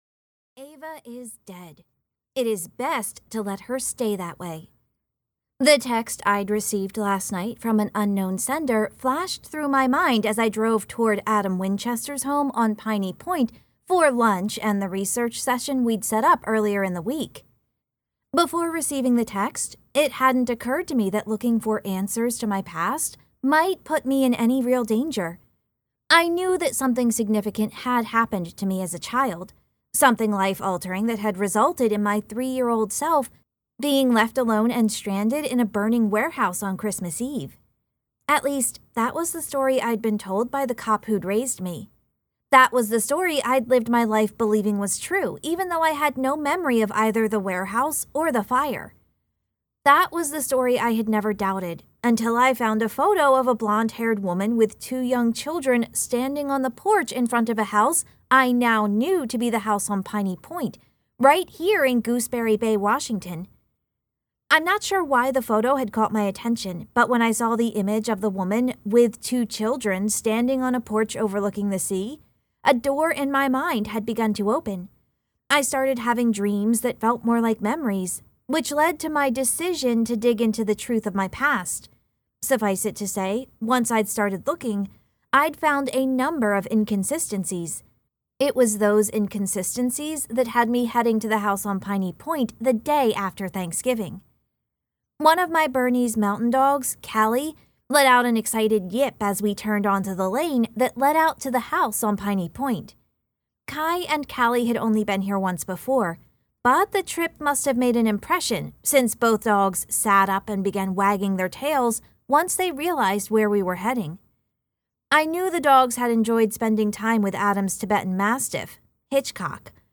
Book 3 Retail Audio Sample Gooseberry Christmas Cottage on Gooseberry Bay.mp3